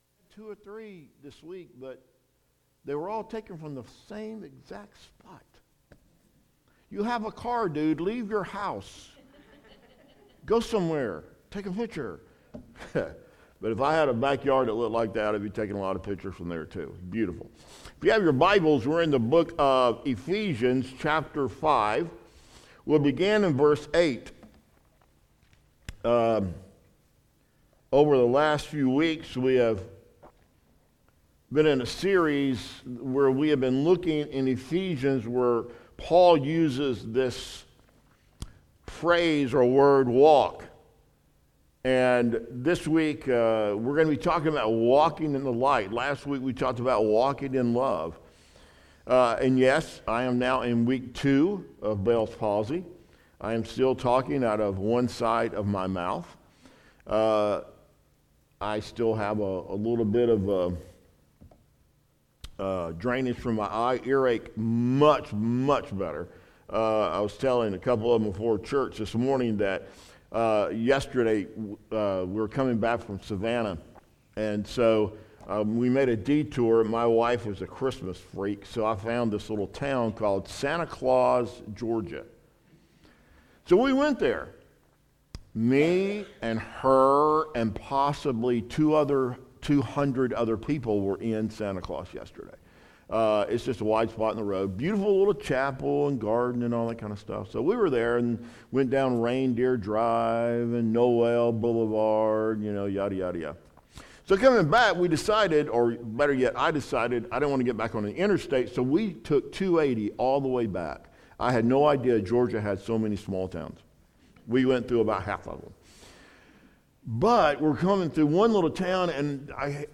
Lake Pointe Baptist Church Weekly Messages